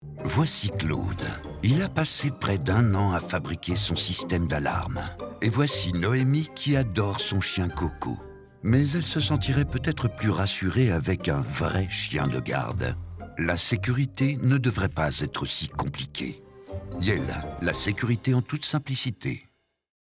Voix off
Pub Yale connivence
Jovial, chaleureux ou autoritaire au théâtre ou au micro, "à mon insu de mon plein gré", ma voix grave est déterminante, aussi ai-je appris à en sortir))).